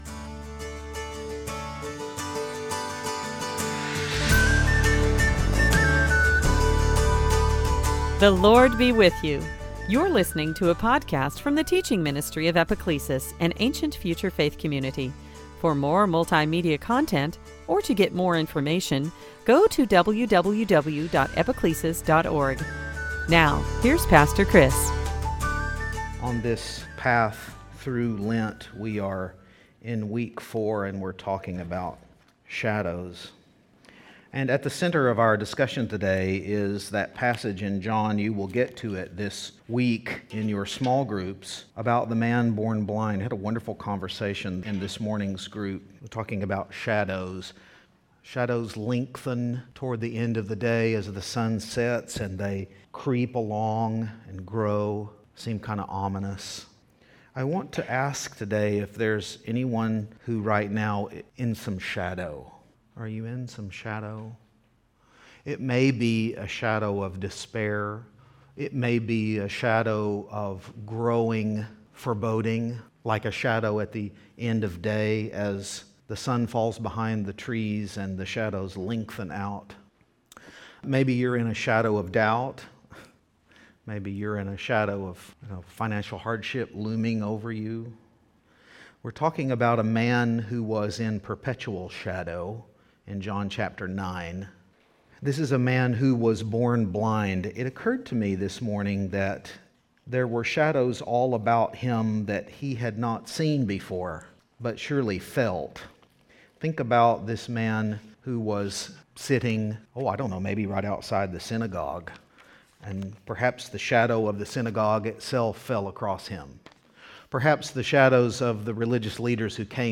Series: Sunday Teaching On this Sunday during Lent